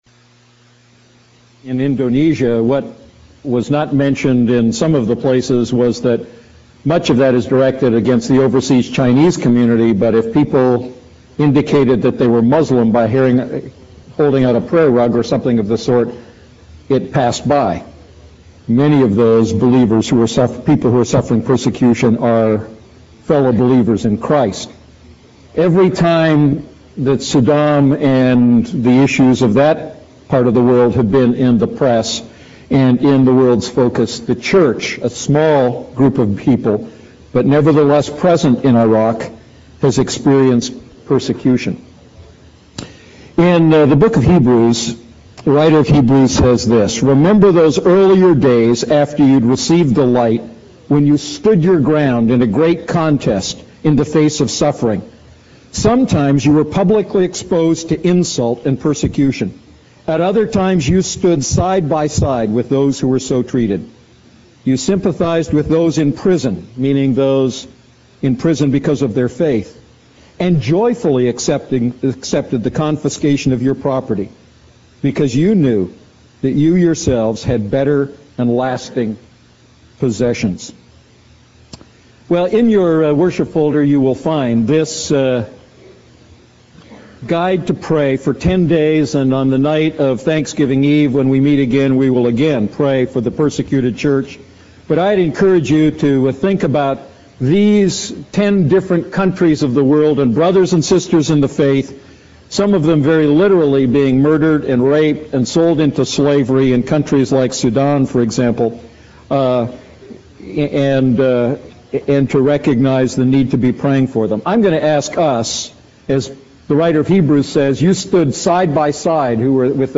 A message from the series "Ephesians Series."